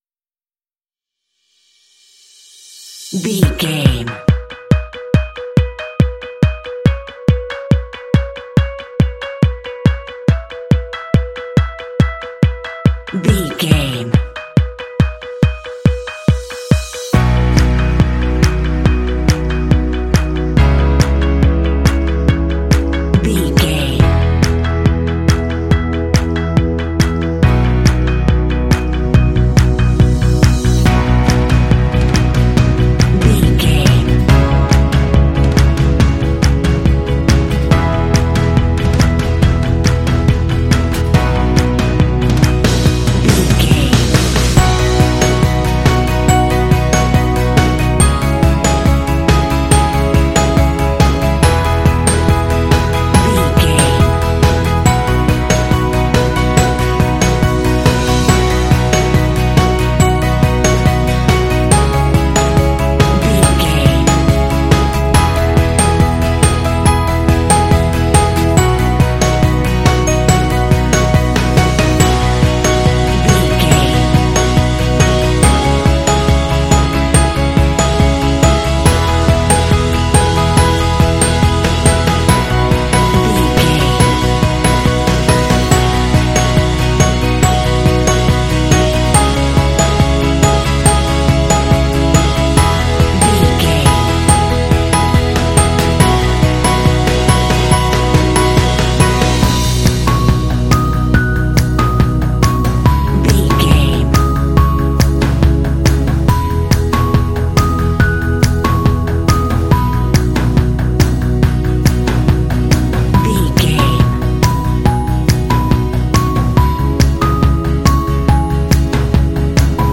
Ionian/Major
uplifting
driving
joyful
electric guitar
bass guitar
drums
percussion
piano
acoustic guitar
rock
pop
alternative rock
indie